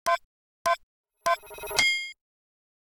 Countdown (1).wav